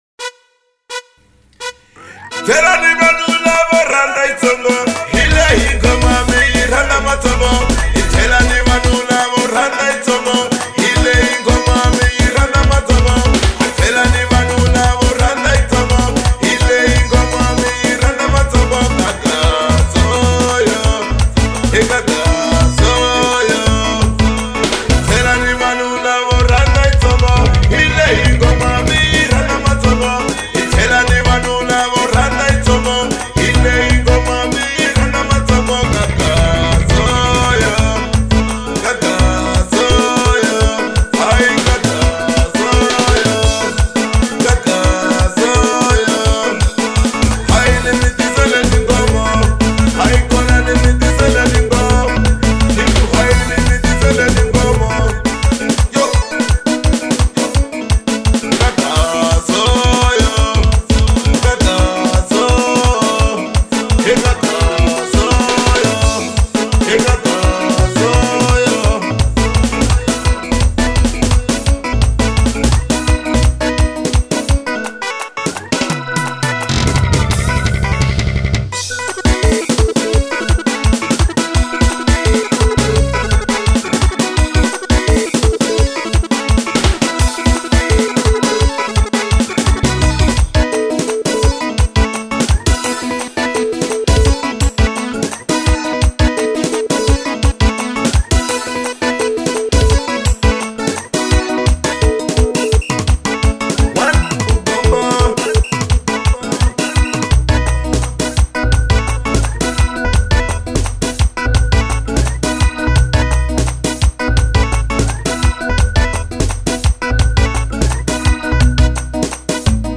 03:40 Genre : Xitsonga Size